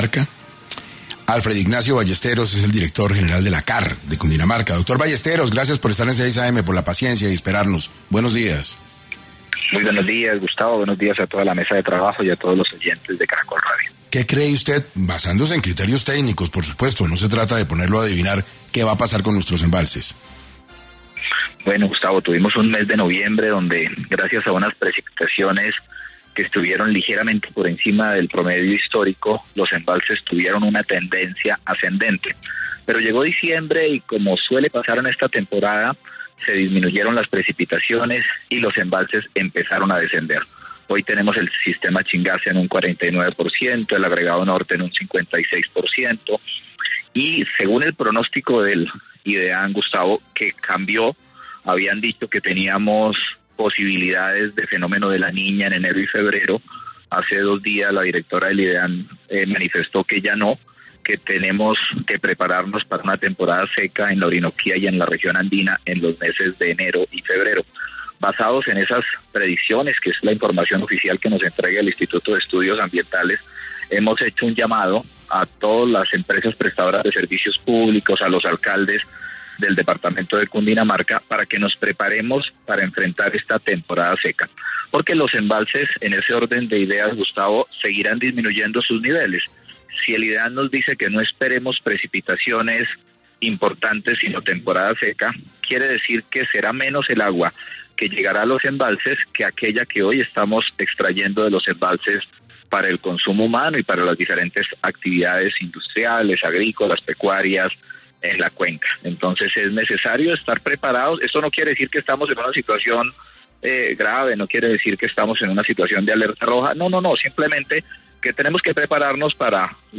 En 6AM de Caracol Radio estuvo Alfred Ignacio Ballesteros, director general de la CAR quien enfatizó que está revisando que porcentaje vendría del agregado norte pero la ciudad tendrá el agua que necesita para afrontar la temporada seca